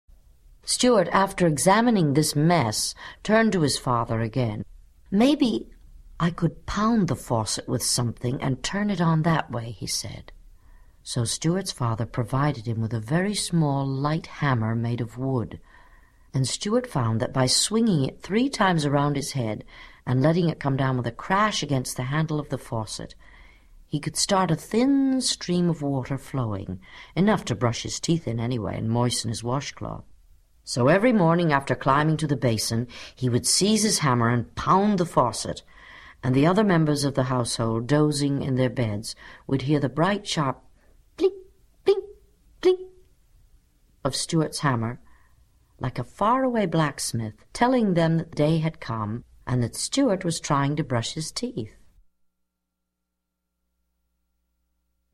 在线英语听力室精灵鼠小弟 第12期:用锤子打开水龙头的听力文件下载, 《精灵鼠小弟》是双语有声读物下面的子栏目，是学习英语，提高英语成绩的极好素材。本书是美国作家怀特(1899—1985)所著的三部被誉为“二十世纪读者最多、最受爱戴的童话”之一。